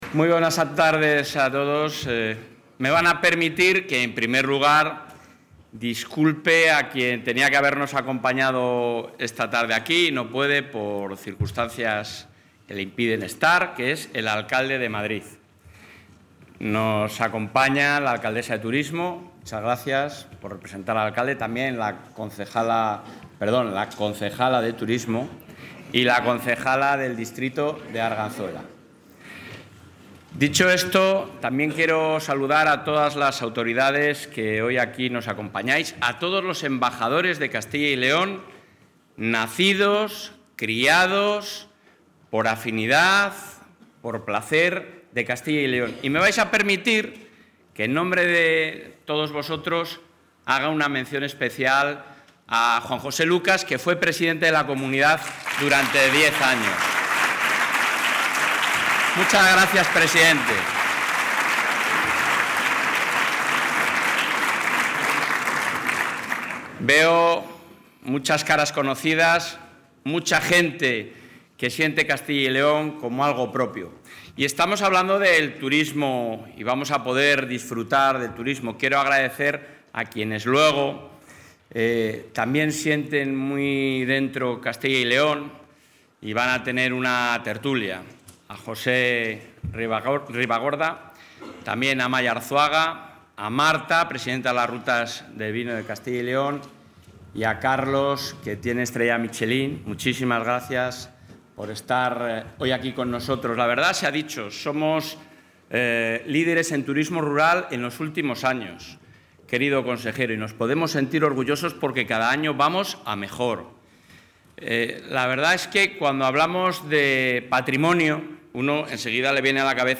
Intervención del presidente de la Junta.
El titular del Ejecutivo autonómico, Alfonso Fernández Mañueco, ha destacado en Madrid, durante el acto de promoción turística de Castilla y León, que la Comunidad lleva cinco años como líder en turismo rural, lo que le sitúa también en la élite turística mundial.